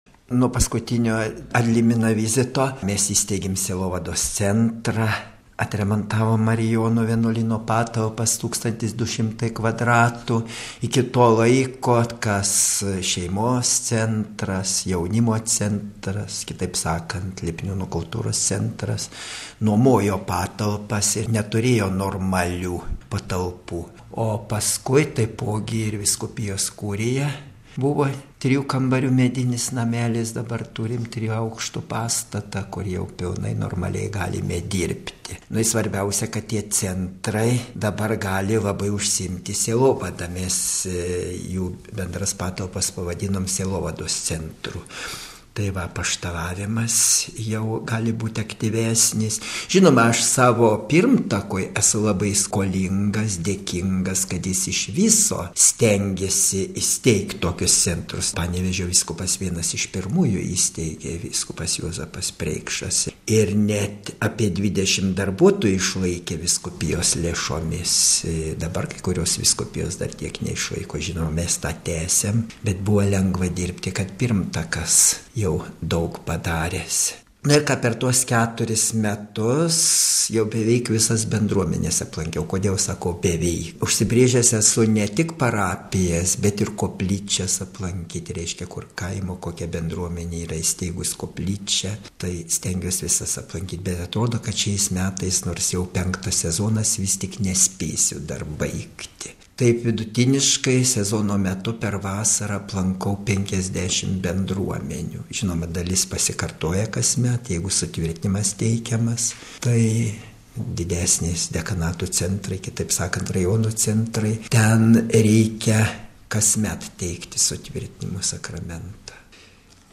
Šioje laidoje tęsiame vizito „Ad Limina“ pradžioje, dar praeitą savaitę, pradėtus mūsų susitikimus su Lietuvos vyskupais. Šiandien prie mūsų mikrofono Panevėžio vyskupas Jonas Kauneckas: RealAudio